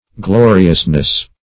-- Glo"ri*ous*ness, n. --Udall.